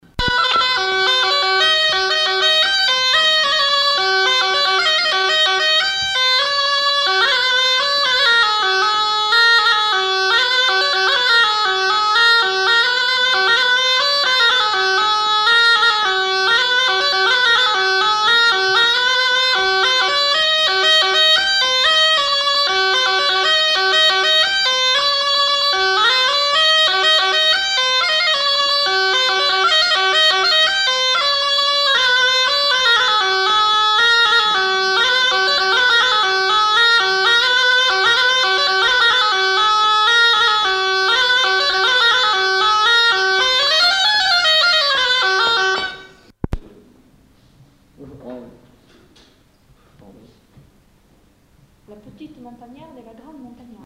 Lieu : Marvejols
Genre : morceau instrumental
Instrument de musique : cabrette
Danse : bourrée